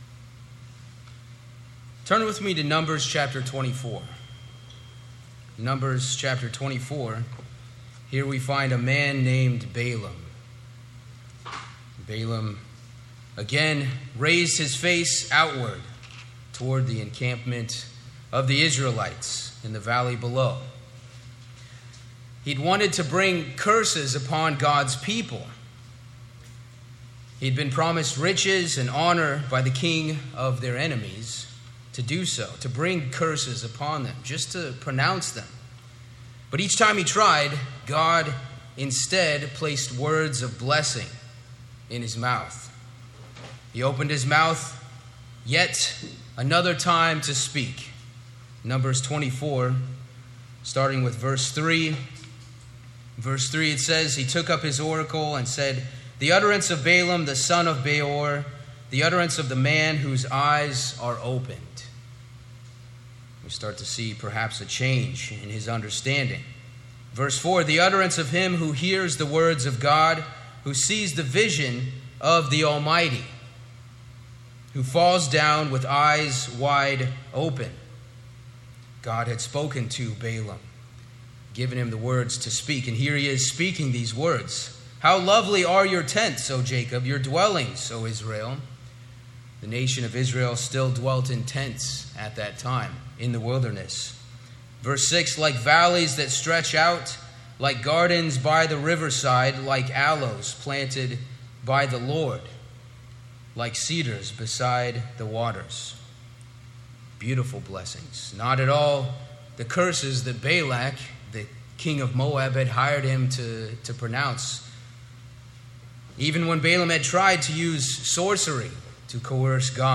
Today the dangers of compromise continue to work against the Church of God, causing harm to their relationship with their Creator. What are some solutions to the dangers of spiritual drift? This sermon is the fourth in a series based on the letters to the congregations found in the book of Revelation.